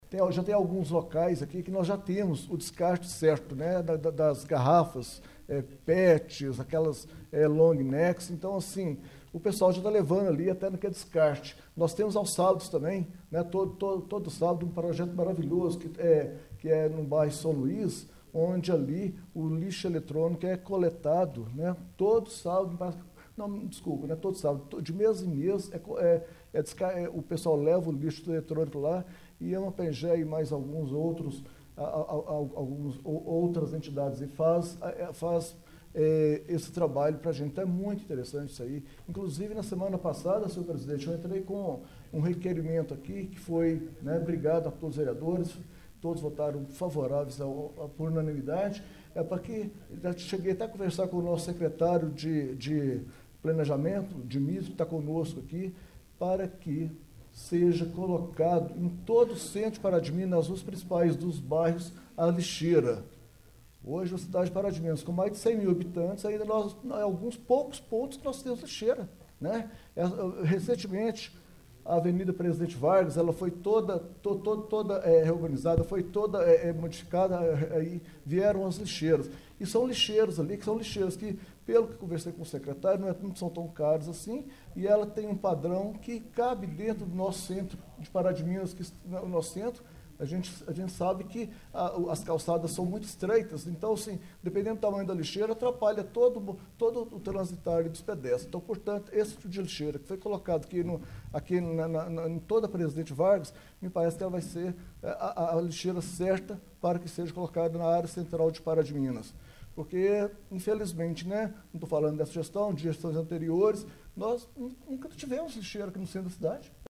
O Portal GRNEWS acompanhou no plenário da Câmara de Vereadores de Pará de Minas mais uma reunião ordinária na noite desta Quarta-Feira de Cinzas, 02 de março.